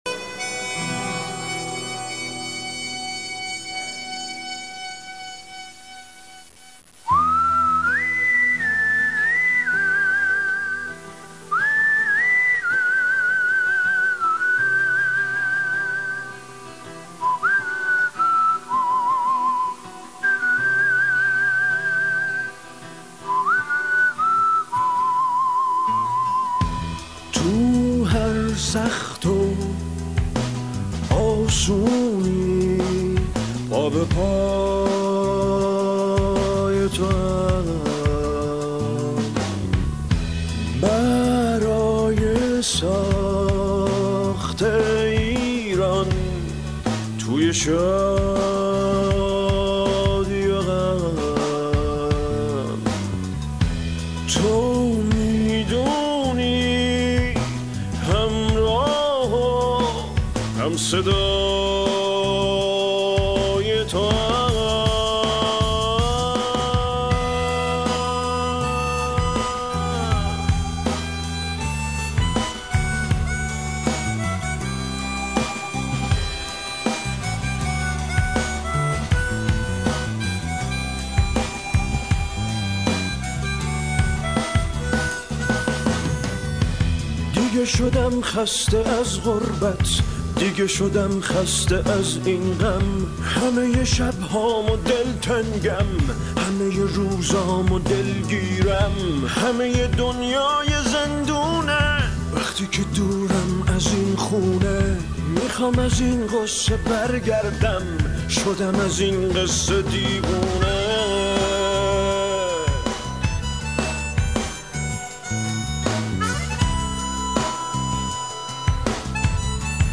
سبک : راک